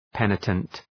{‘penətənt}